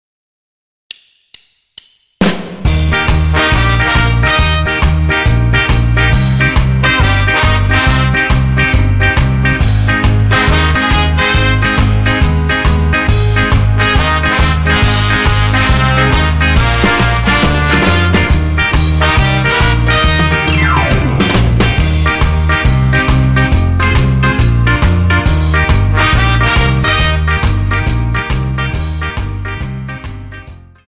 --> MP3 Demo abspielen...
Tonart:Eb ohne Chor